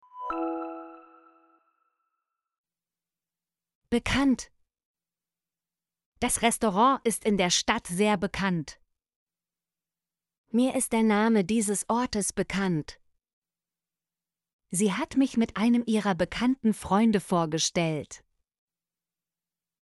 bekannt - Example Sentences & Pronunciation, German Frequency List